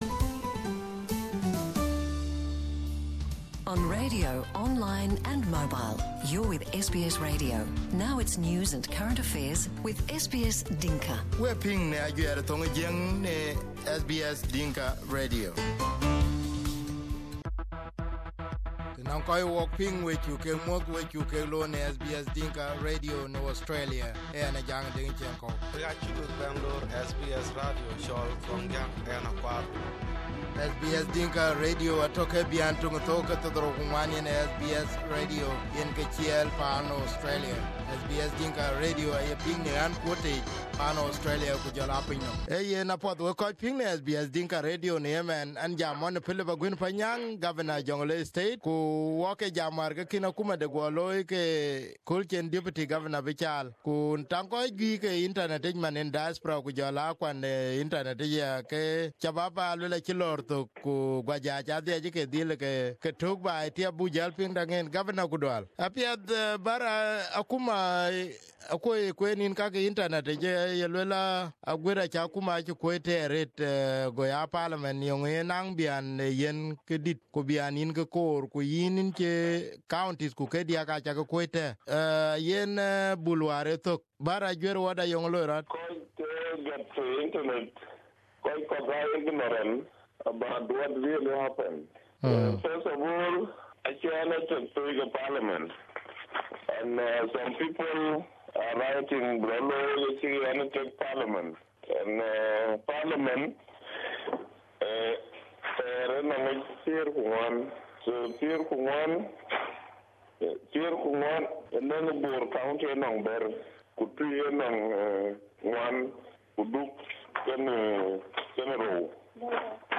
After the 28 states were announced, 7 more seats were to be added in order to bring Jongleis parliament to the total of 21 MP's. Now the division of those twenty one seats caused much problem between the communities involved. In this interview with SBS Dinka Radio, Governor Philip Aguer Panyang narrated the story and said that he was not involved in the division of those seats.